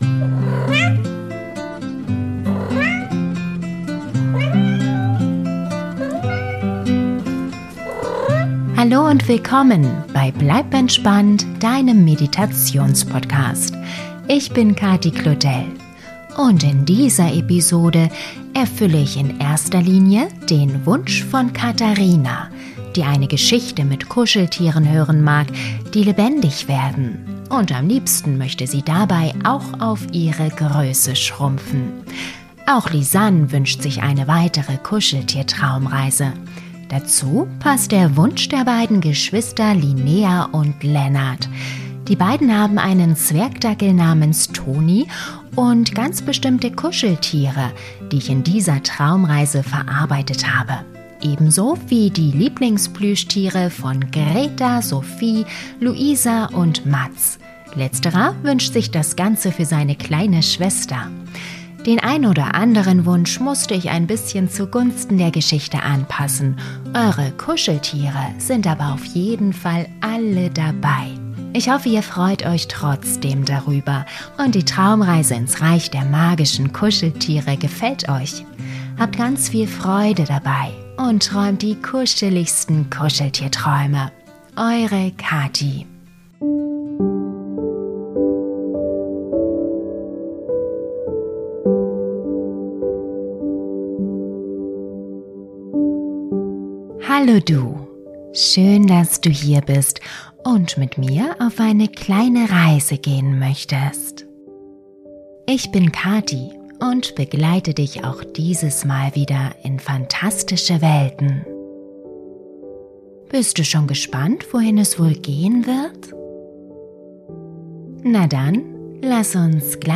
Diese Traumreise für Kinder ist die perfekte, entspannende Gute Nacht Geschichte zum Anhören für die ganze Familie in der Vorweihnachtszeit.